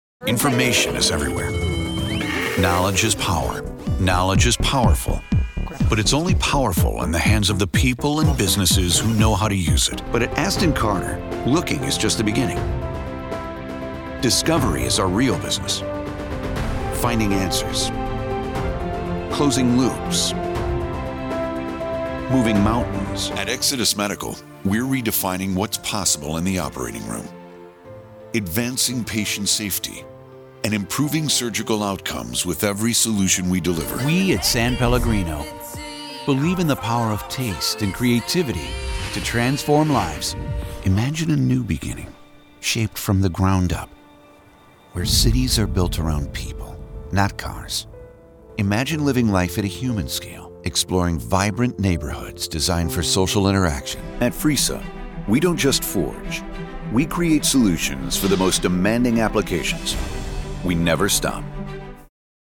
Never any Artificial Voices used, unlike other sites.
Male
Adult (30-50), Older Sound (50+)
His voice ranges from friendly and conversational to dramatic, informational and gritty.
Narration